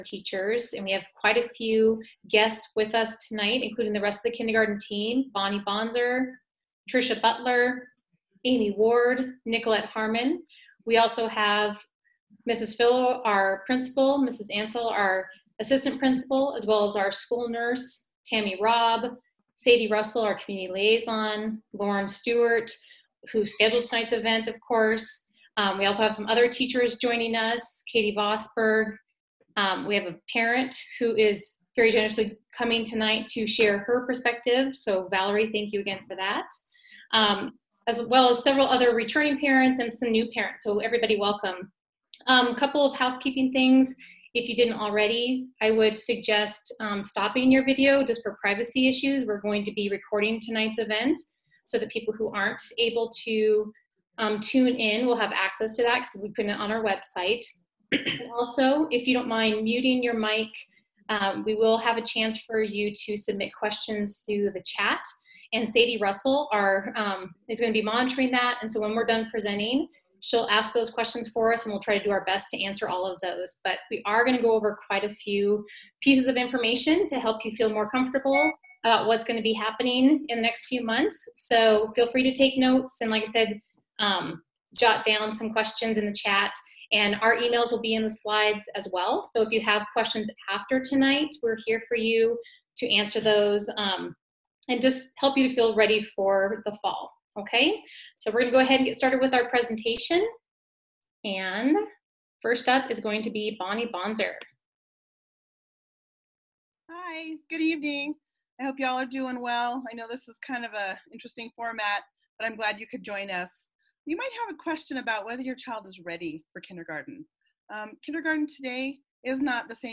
Kindergarten Readiness Night Presentation